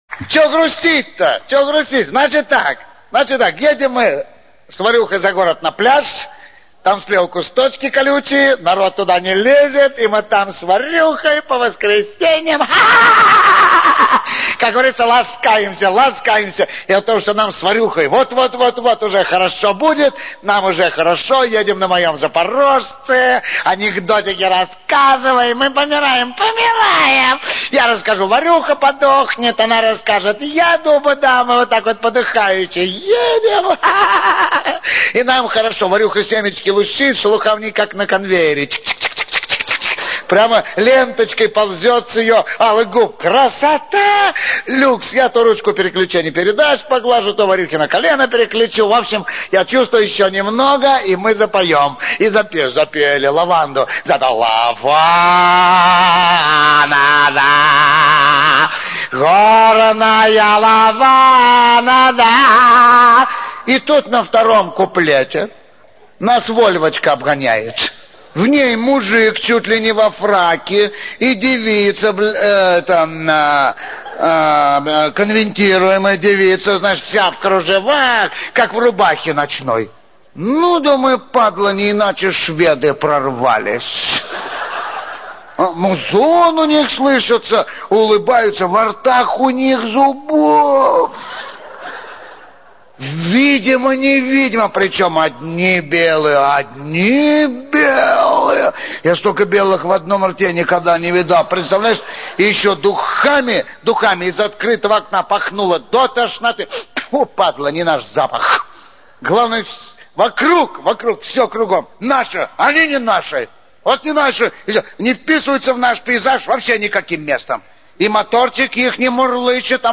Улётно смешной рассказ от Генадия Хазанова